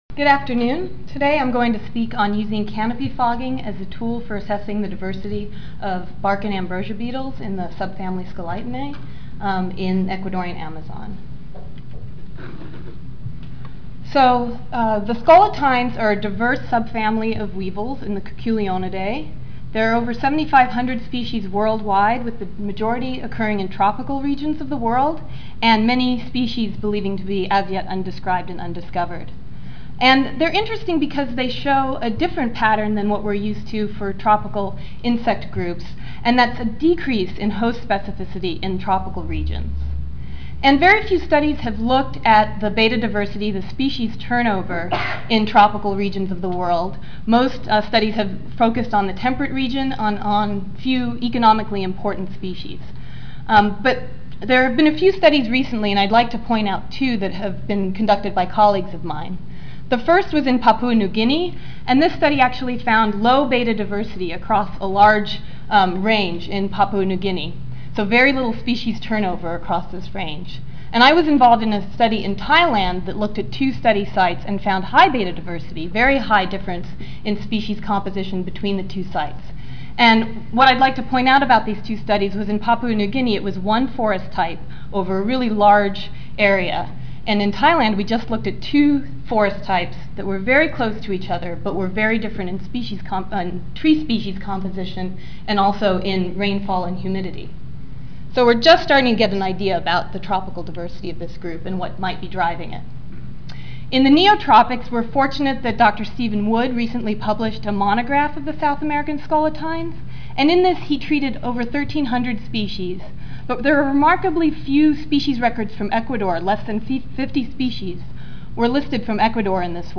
Ten Minute Paper (TMP) Oral